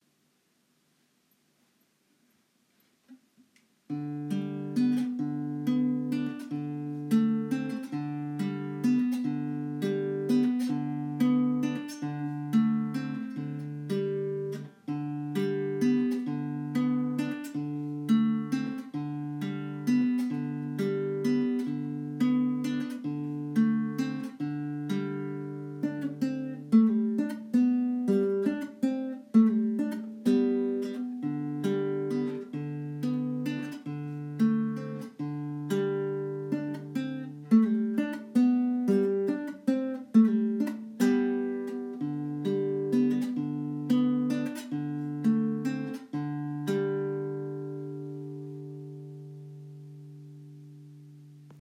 Här kommer ett nytt” stapplande” notspelsförsök. ”Vals i G” heter det lilla stycket och ljudkvaliteten är kass men ändå (en vän säger till mig att sluta ursäkta mig hela tiden med ”stapplande”  och ”dålig kvalitet”… för det är ju bara för att det är så orimligt svårt att stå för något man gör när man inte kan och det blir läskigt och man är så sårbar för kritik så man vill försöka ”bädda in sig” i ursäkter).